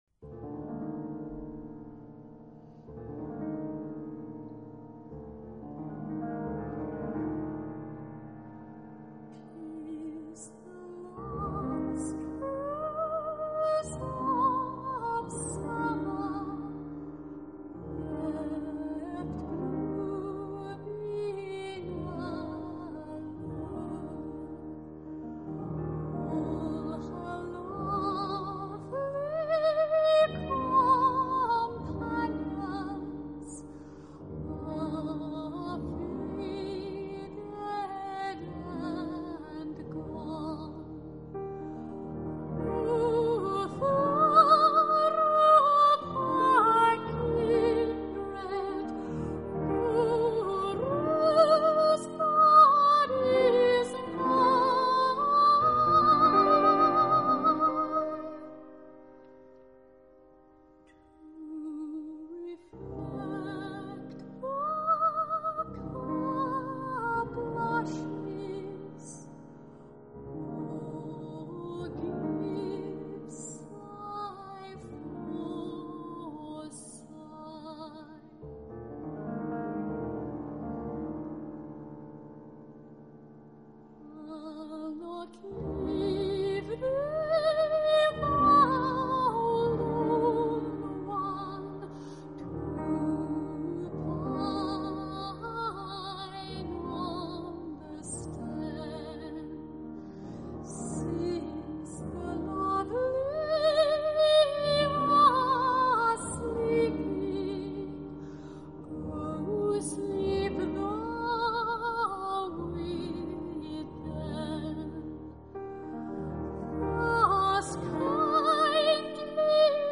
【天籁美声】